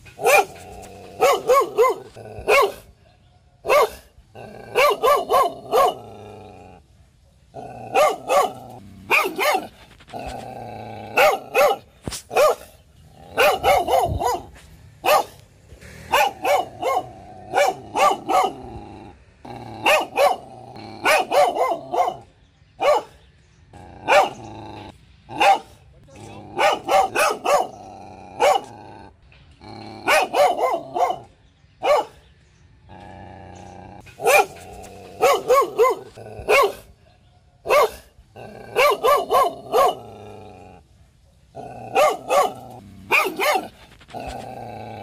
Tiếng Chó Sủa Gâu Gâu MP3